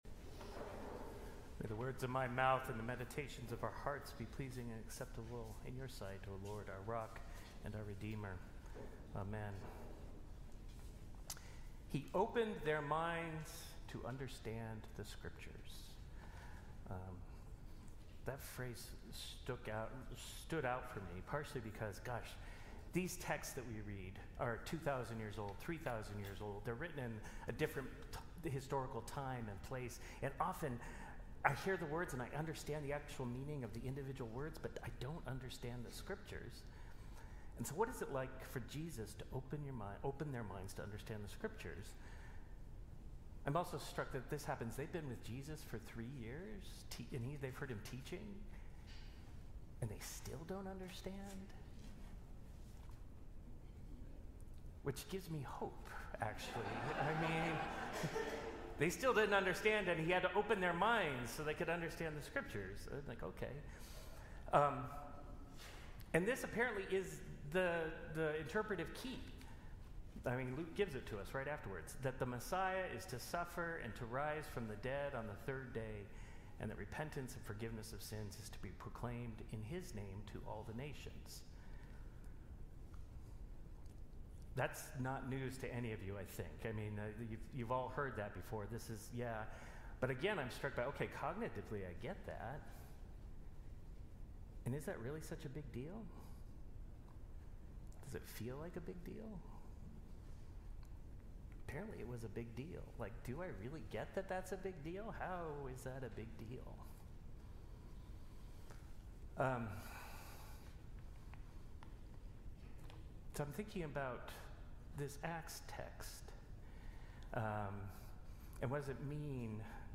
Sermons | Grace Episcopal Church
The Seventh Sunday of Easter/Epiphany